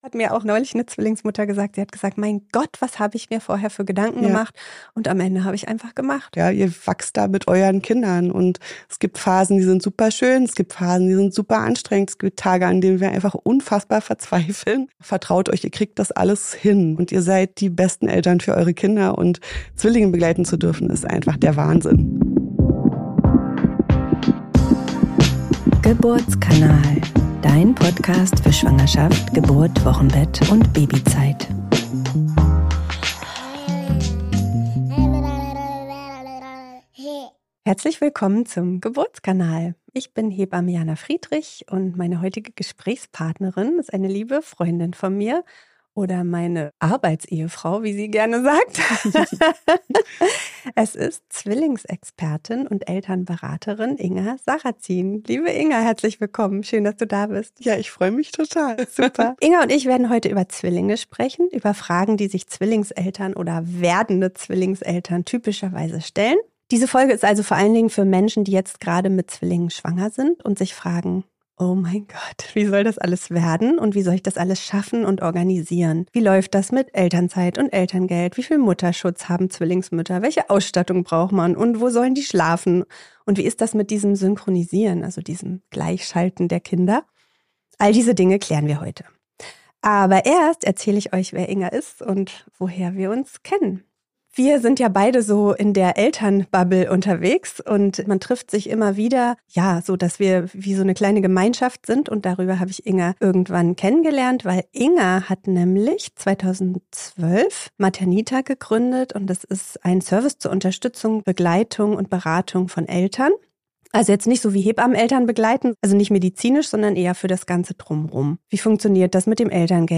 Diesmal dreht sich alles um das Leben mit Babys im Doppelpack. Ich spreche ich mit